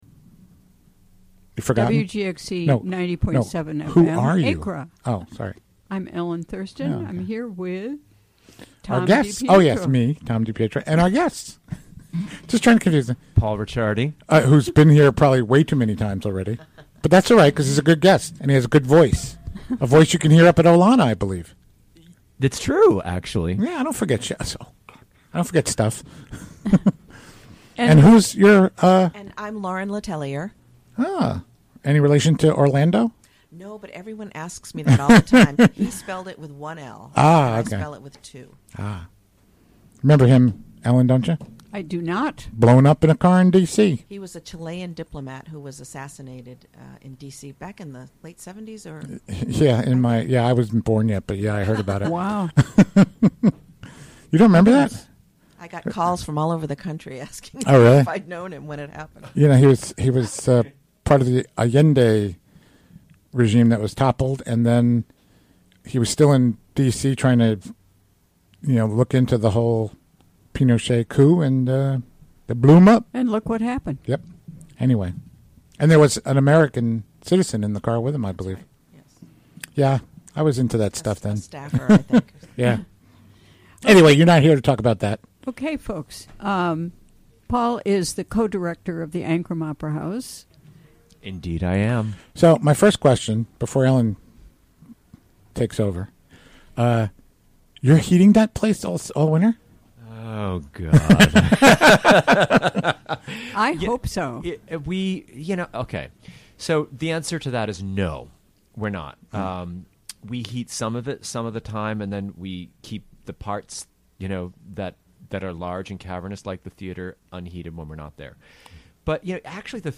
Recorded during the WGXC Afternoon Show Thursday, December 15, 2016.